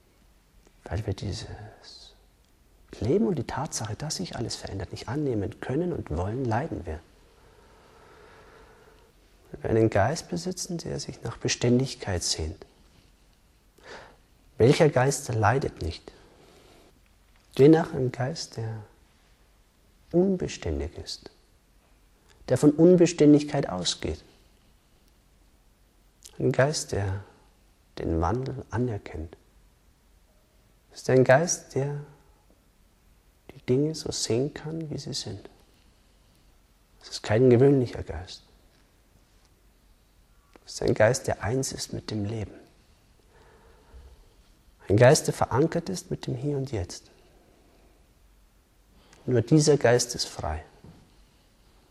Eine Live-Aufnahme aus einem Satsang, die dir grundlegende Erkenntnisse über Meditation und tiefe Stille schenkt